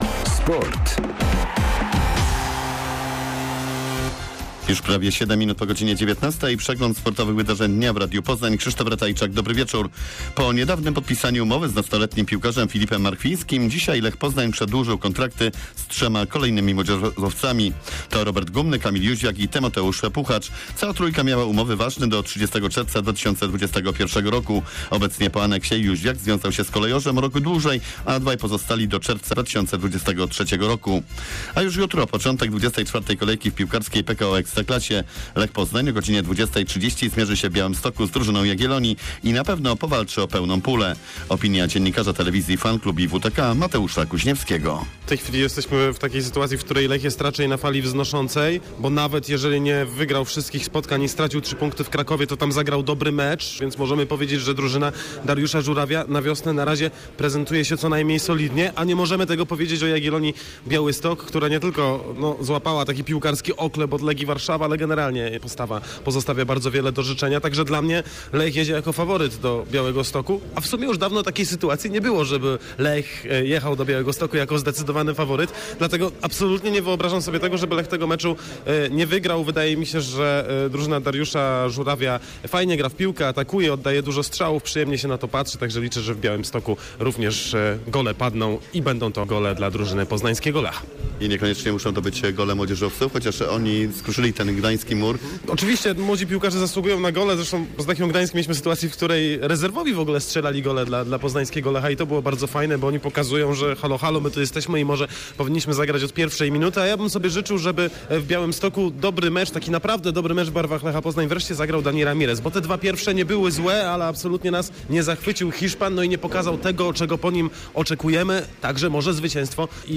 27.02. SERWIS SPORTOWY GODZ. 19:05